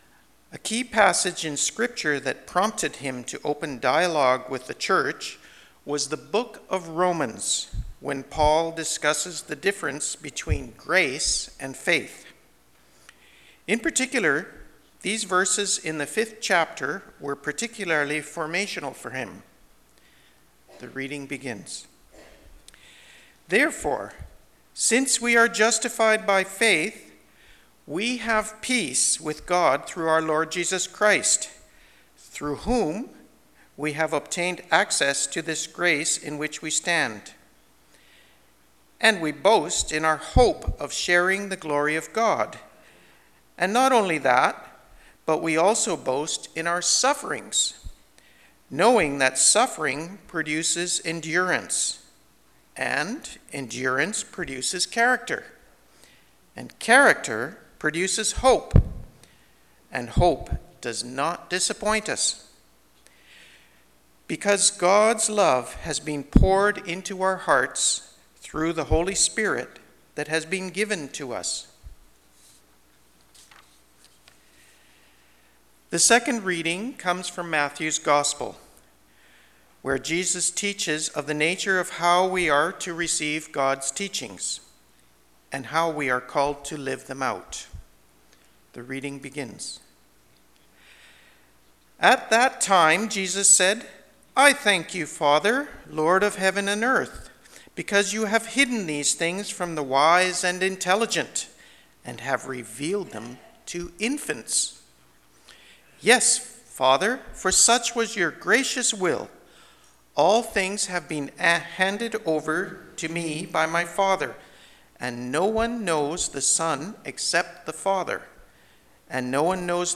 Sermons | Northwood United Church